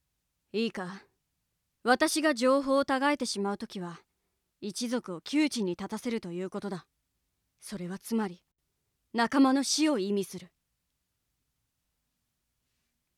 （子どもに向かってではなく、一人前の仲間としての立場から）